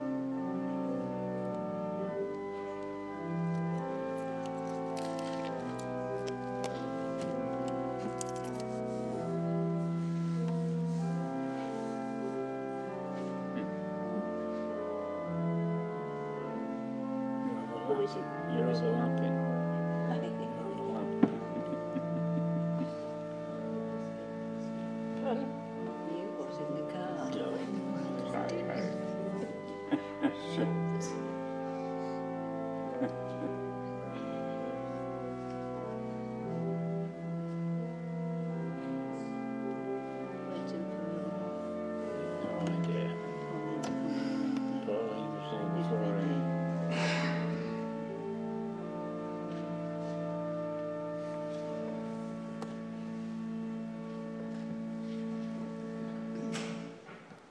Organ music before my Dad's last service in his current parish.